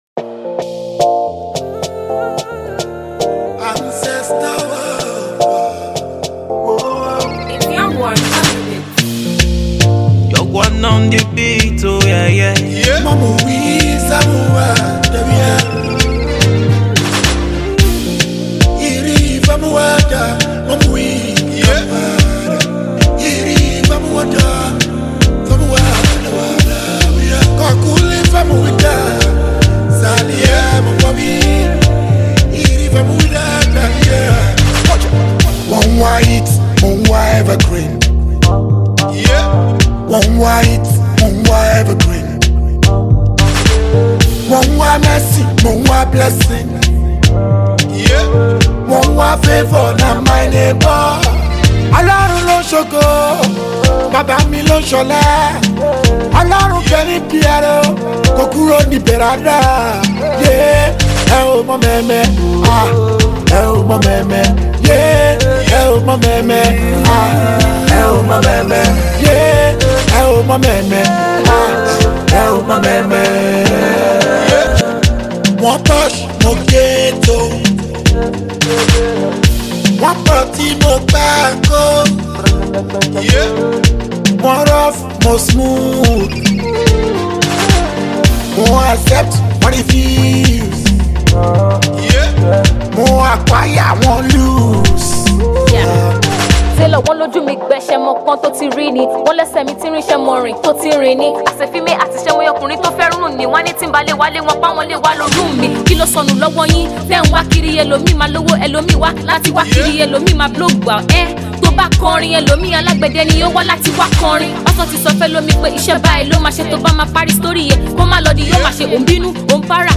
he features young female Rapper